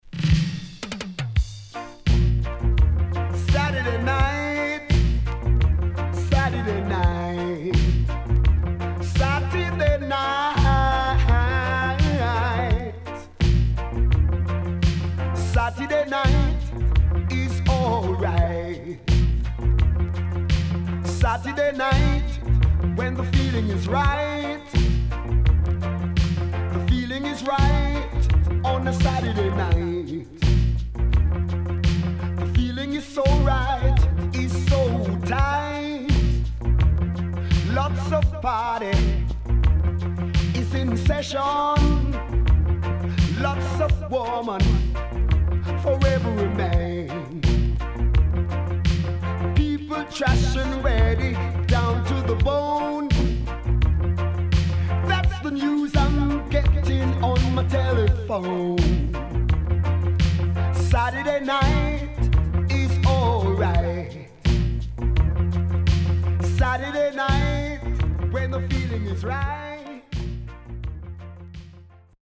HOME > Back Order [DANCEHALL LP]
SIDE A:少しノイズ入りますが良好です。